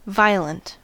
Ääntäminen
IPA : /ˈvaɪ(ə)lənt/